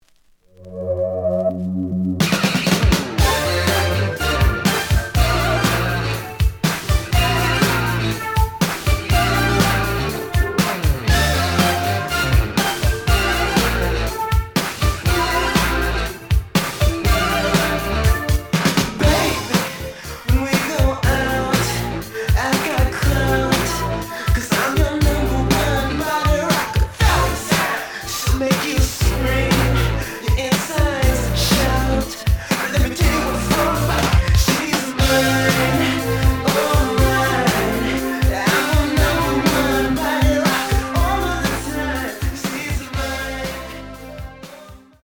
試聴は実際のレコードから録音しています。
●Genre: Funk, 80's / 90's Funk
●Record Grading: EX- (盤に若干の歪み。多少の傷はあるが、おおむね良好。)